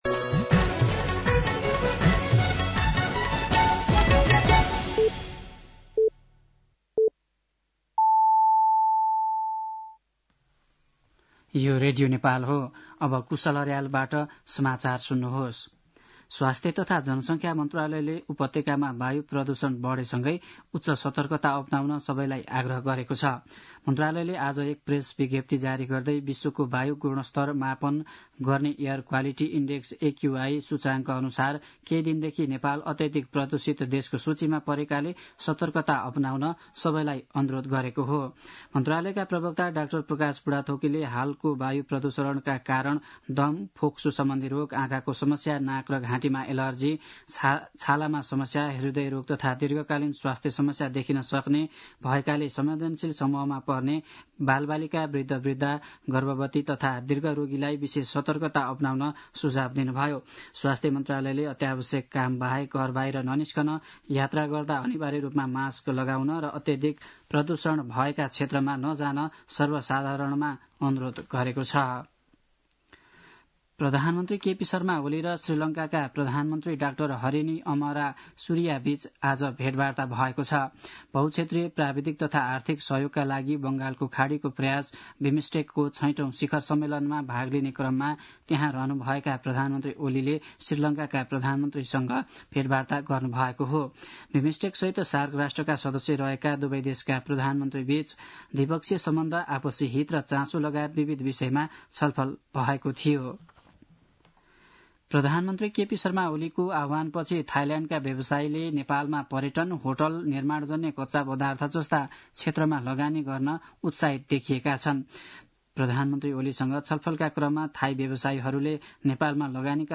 साँझ ५ बजेको नेपाली समाचार : २१ चैत , २०८१
5-pm-news-1.mp3